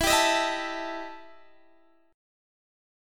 EmM7bb5 chord